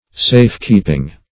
Safe-keeping \Safe"-keep"ing\, n. [Safe + keep.]